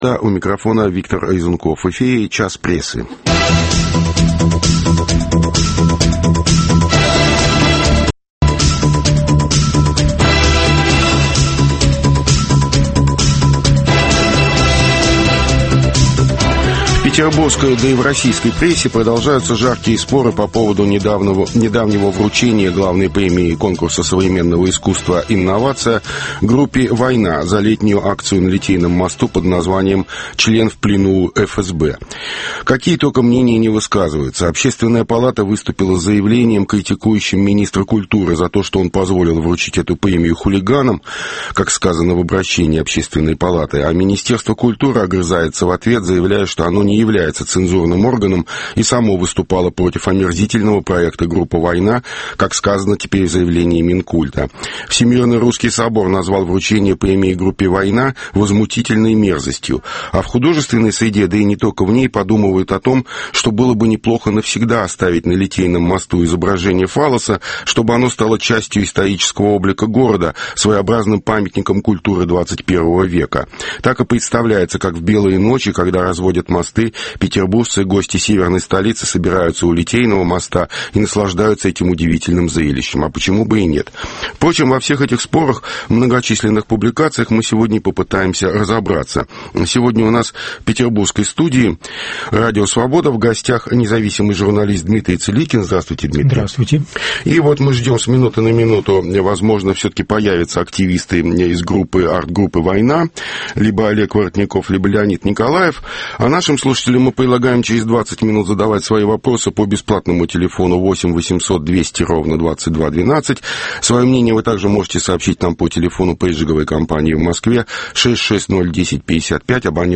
Почему не стихают страсти вокруг группы "Война"? В студии